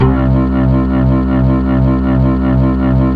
B3 TONE C2.wav